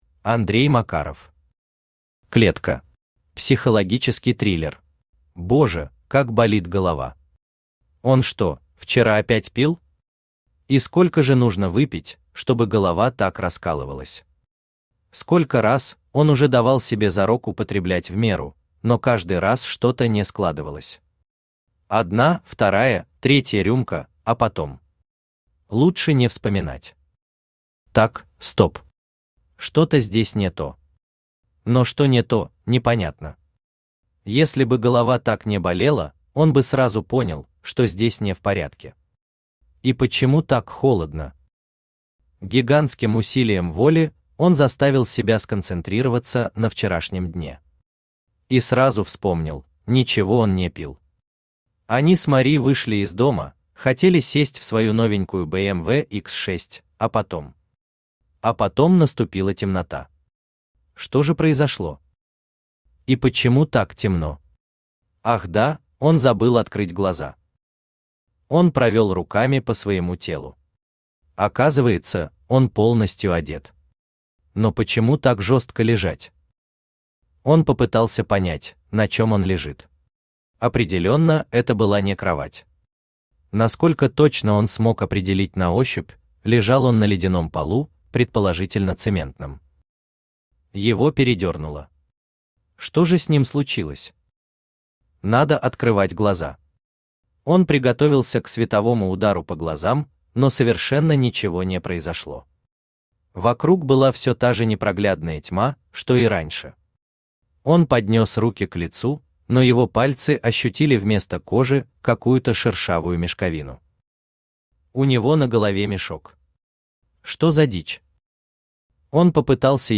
Аудиокнига Клетка. Психологический триллер | Библиотека аудиокниг
Психологический триллер Автор Андрей Олегович Макаров Читает аудиокнигу Авточтец ЛитРес.